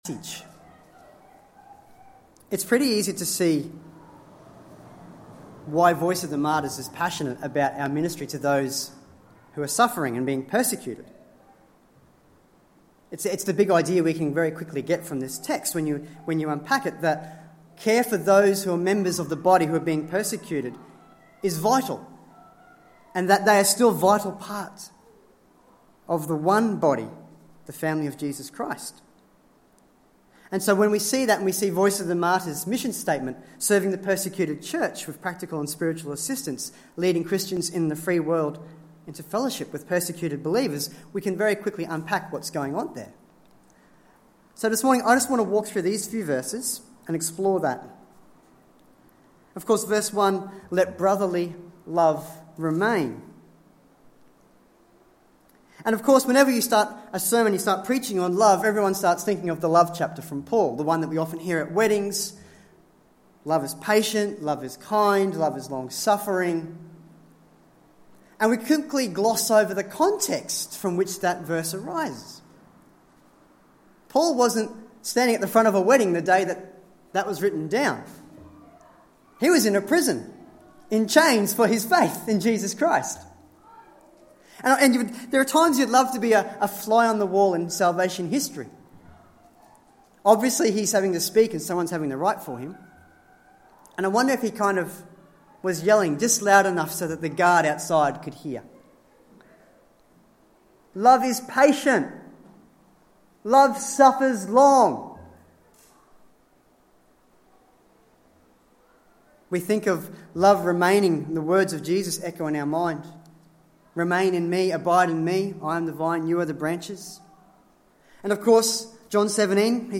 Sermons | Tenthill Baptist Church
20/02/2022 Sunday service